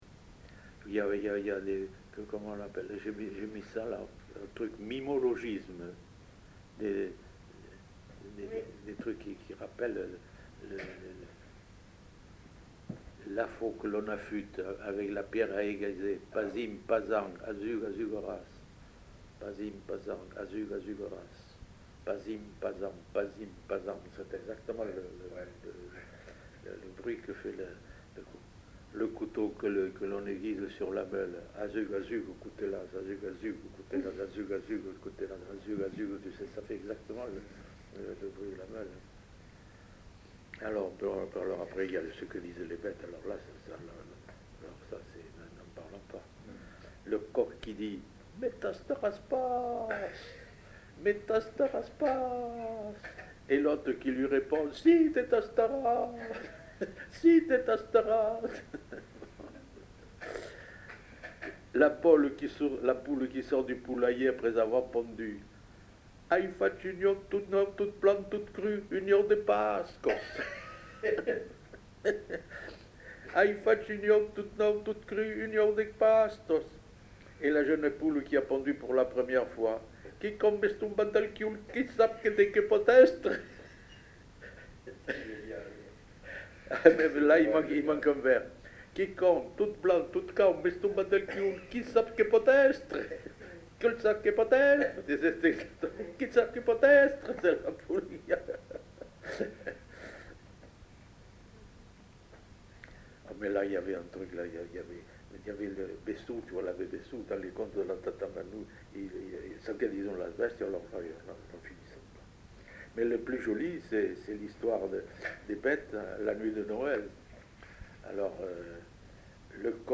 Lieu : Saint-Sauveur
Genre : forme brève
Type de voix : voix d'homme
Production du son : récité
Classification : mimologisme